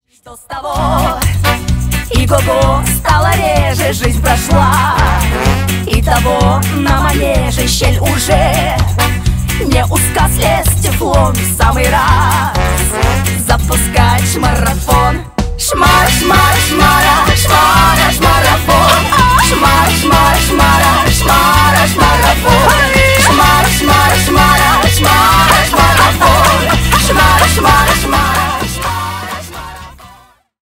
Рок Металл
весёлые